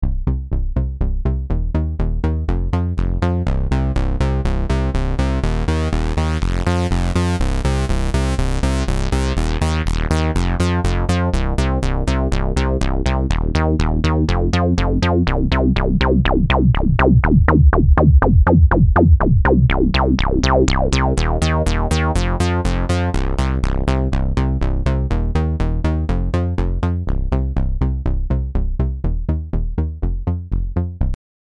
Naja, ein bißchen Gebrumme.
Nur Low Pass, erst ohne Resonanz, dann zur vollen Resonanz und zurück.